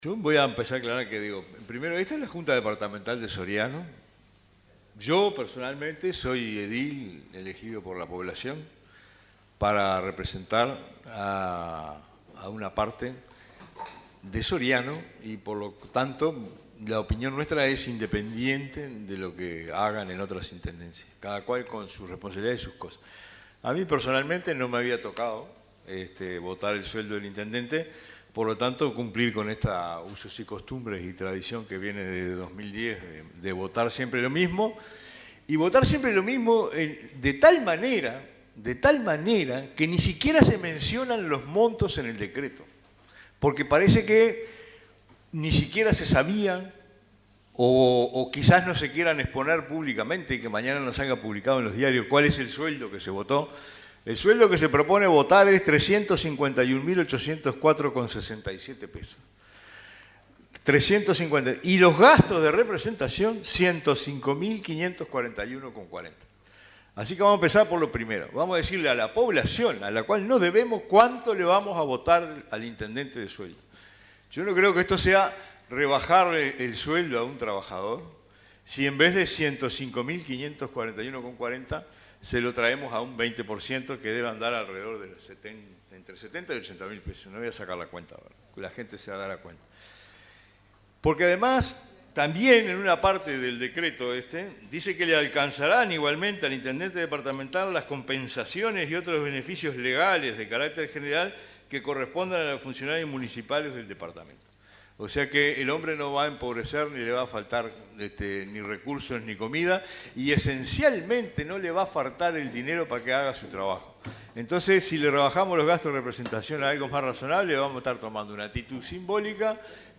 En la sesión ordinaria celebrada este lunes por la Junta Departamental de Soriano, el edil Jorge Cardona del Frente Amplio, dio a conocer el monto del sueldo que percibirá el futuro intendente